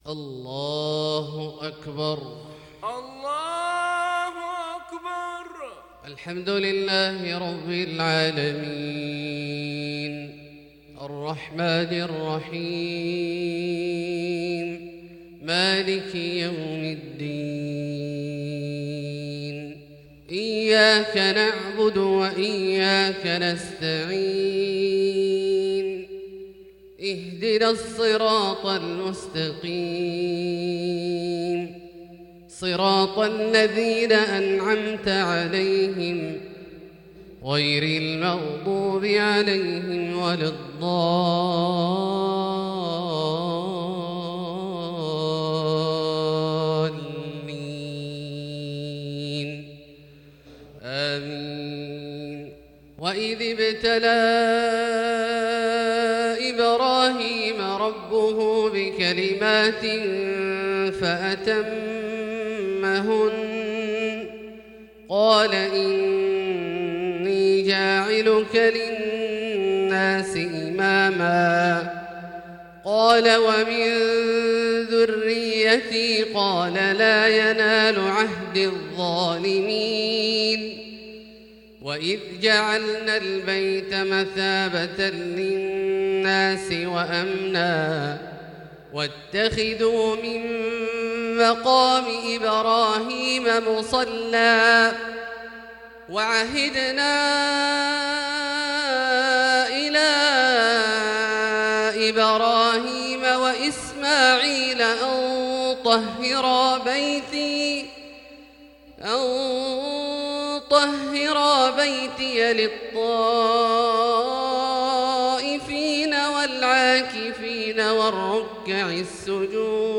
صلاة الفجر للقارئ عبدالله الجهني 26 شعبان 1441 هـ
تِلَاوَات الْحَرَمَيْن .